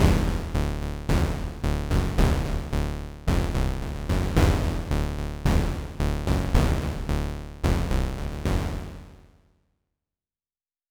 Давайте проверим это, пропуская последнюю барабанную партию через патч синтезатора в Astra:
Звучит неплохо для винтажной игры Марио, когда Боузер входит в комнату, но, возможно, не совсем подходит для вашей музыки.